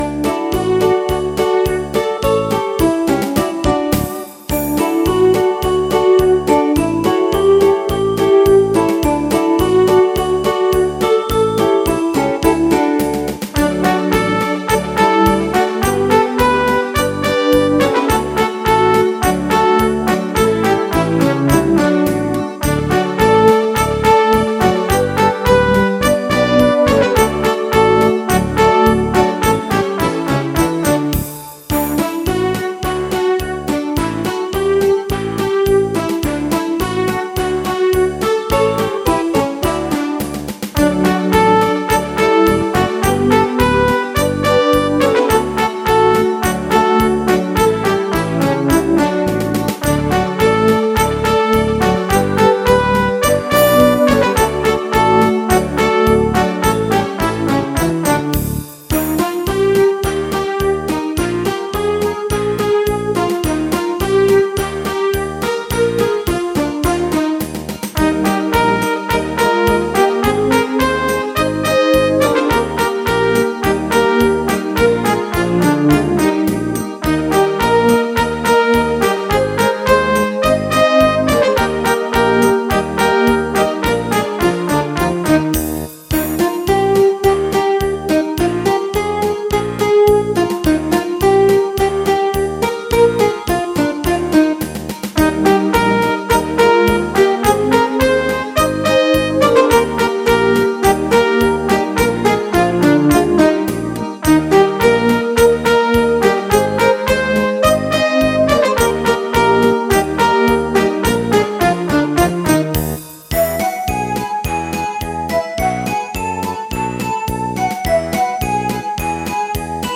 5) Podkłady muzyczne do Przeglądu Tańca Tradycyjnego: wiwat,
krakowiak
krakowiak.mp3